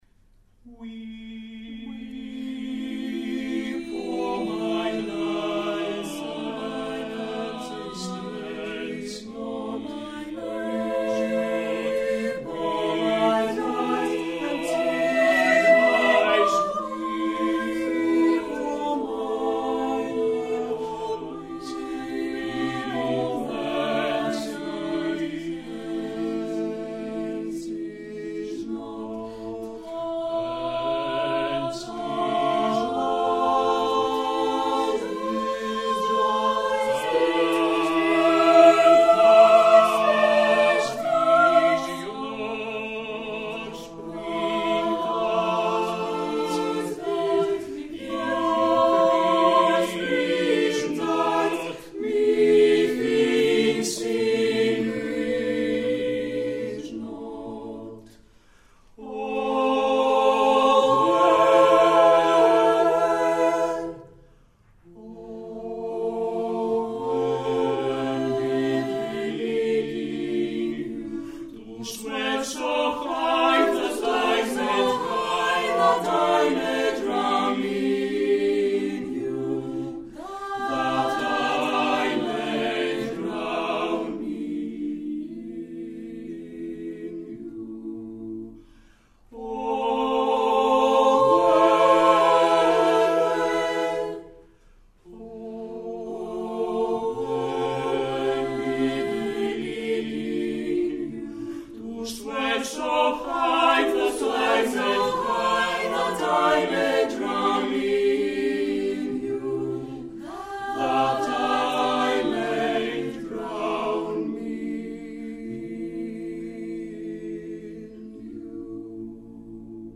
Χωρίς επεξεργασία